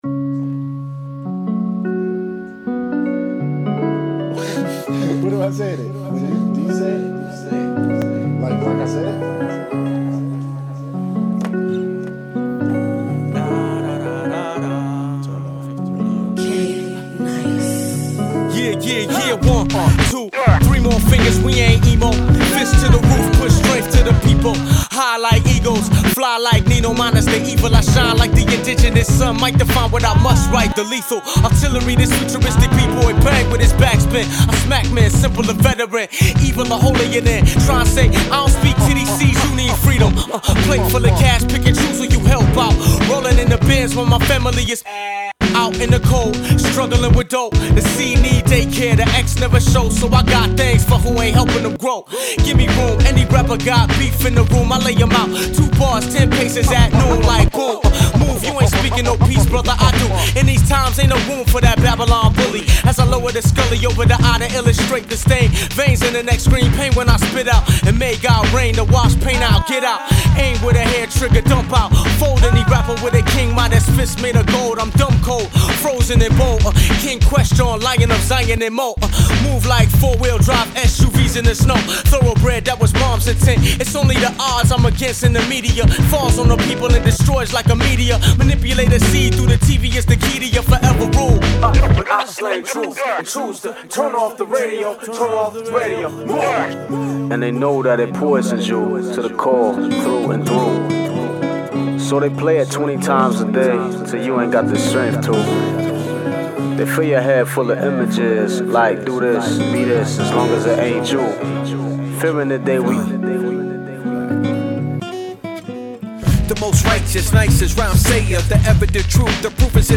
organic sampling and crisp live instrumentation